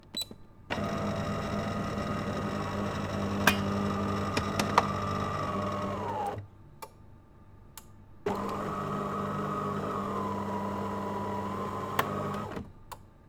coffee_machine.wav